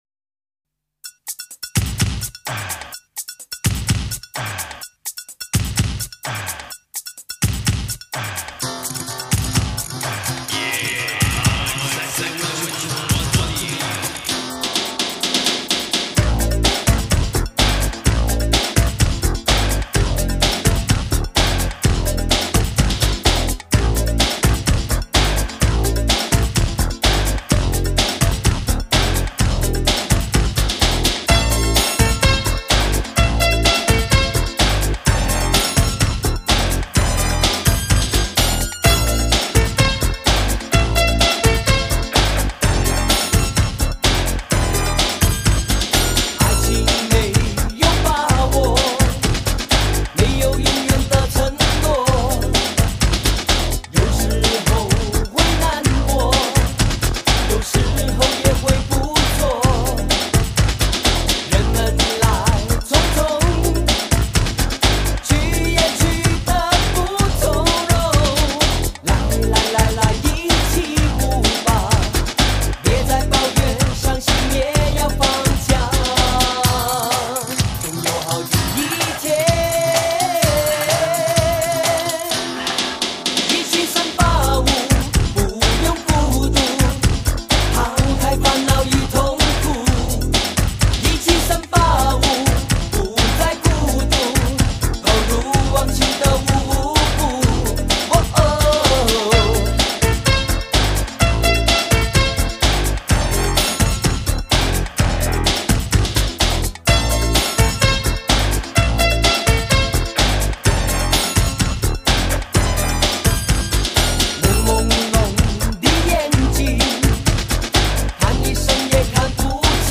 这是是从90年代的卡带翻录的修
劲歌劲舞版的经典老歌，当年的优秀作品.。是最佳车载碟的选择.。